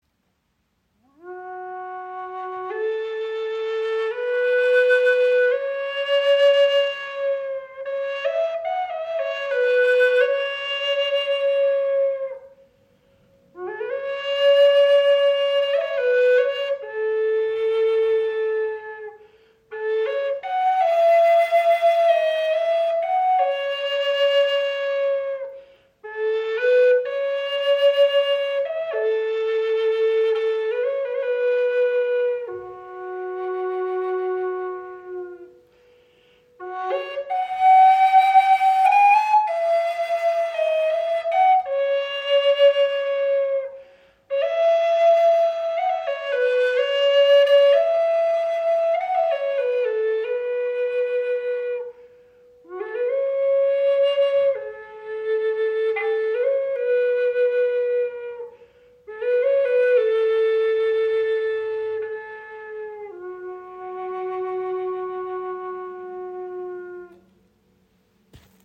• Icon Gesamtlänge 60  cm, 20  mm Innenbohrung – harmonischer Klang
Ihr Klang ist warm, leicht windig und dennoch präsent – ideal für Meditation, Rituale und freies, intuitives Spiel.
Handgefertigte Gebetsflöte in F# aus Amaranth mit Feder-Windblock aus Ivory und Macassar Ebenholz. Warmer, tragender Klang für Meditation, Rituale, individuelle Klangarbeit.